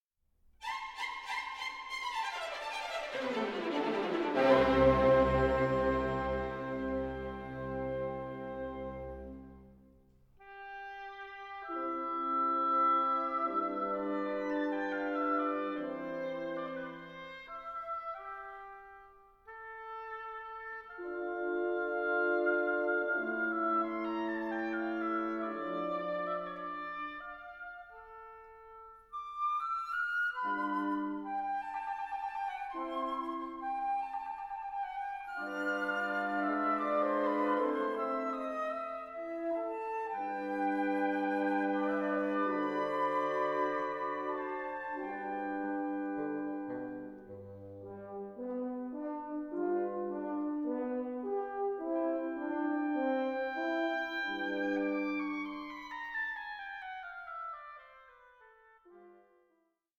ELEGANT, EXHILARATING, ELECTRIFYING!